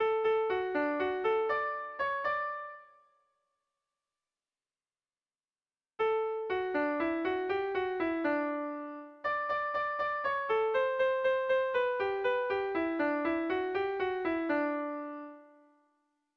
Kontakizunezkoa
8A / 8B / 10A / 8B
ABD